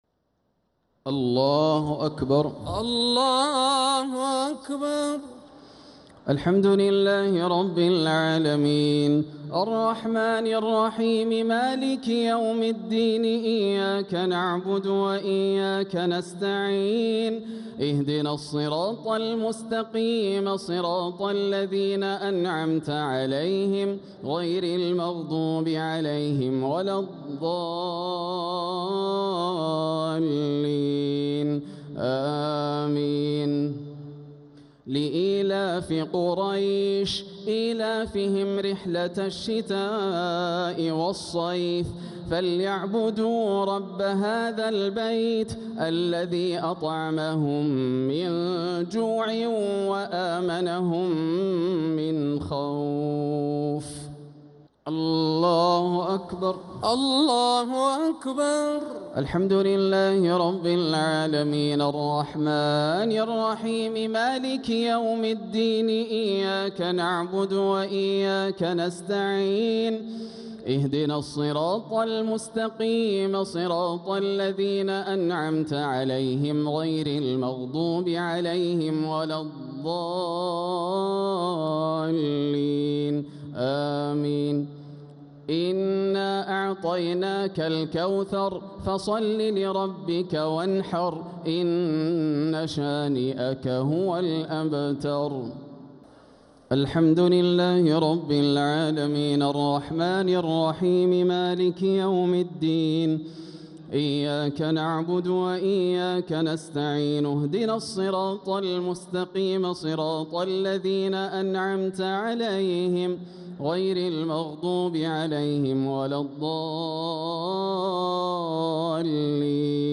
الشفع و الوتر ليلة 12 رمضان 1447هـ | Witr 12th night Ramadan 1447H > تراويح الحرم المكي عام 1447 🕋 > التراويح - تلاوات الحرمين